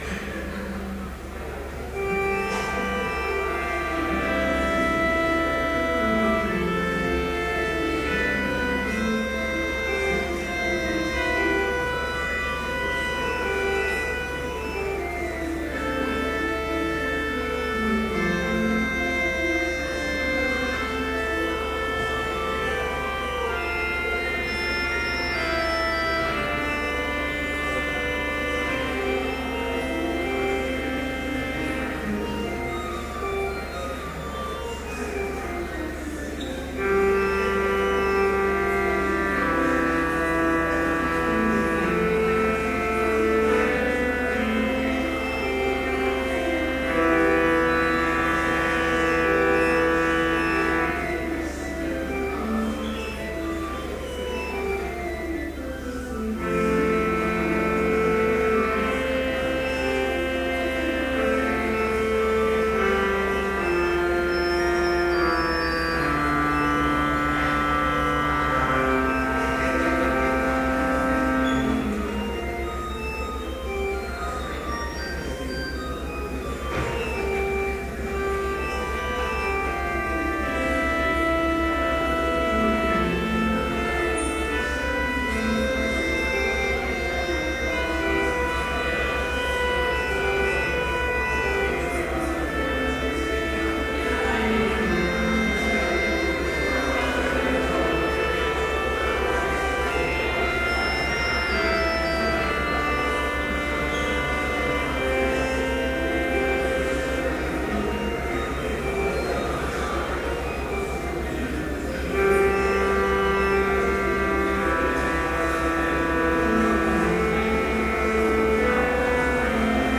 Complete service audio for Chapel - October 3, 2013
Order of Service Prelude Hymn 28, vv. 1-3, O How Holy Is This Place